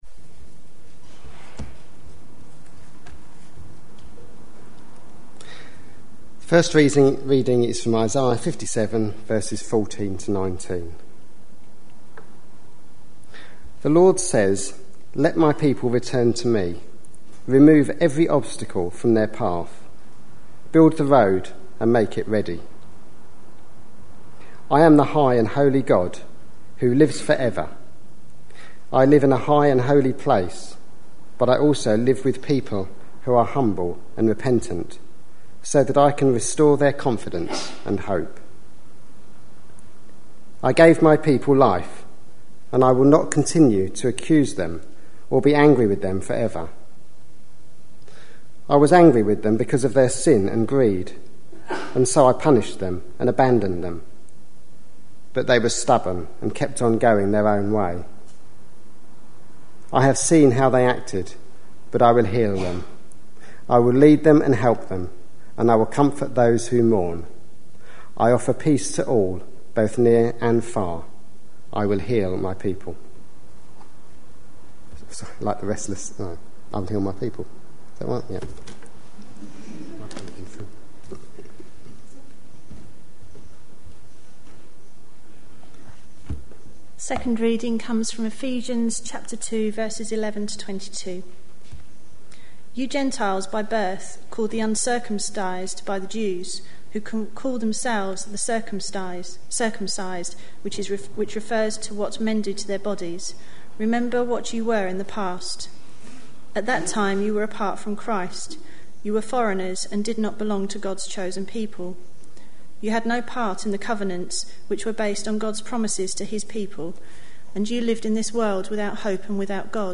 A sermon preached on 17th June, 2012, as part of our A Purple Chapter series.